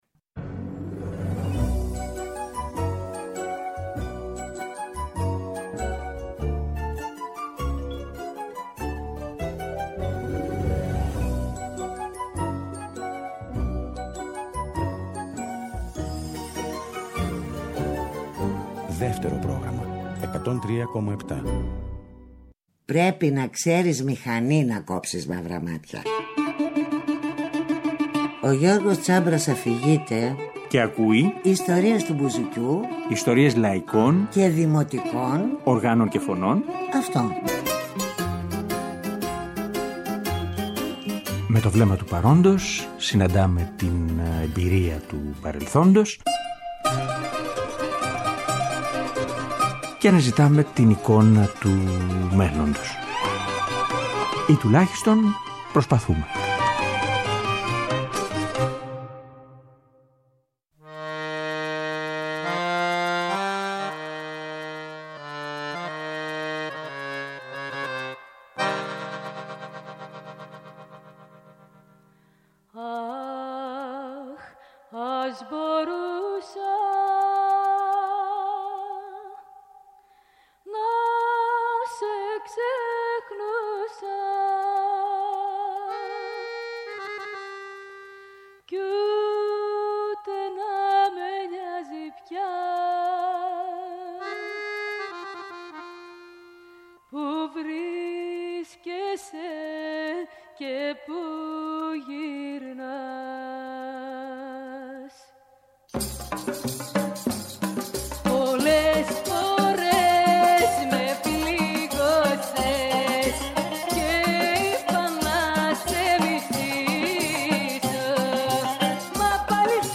Ηχογραφήσεις 1955 – 1995